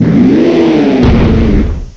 cry_not_turtonator.aif